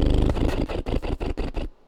sum_airship_lip_trill_end.ogg